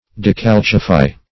Decalcify \De*cal"ci*fy\, v. t. [imp. & p. p. Decalcified; p.